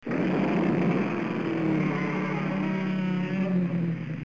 Godzilla Roar 11 Sound Button | Sound Effect Pro
Instant meme sound effect perfect for videos, streams, and sharing with friends.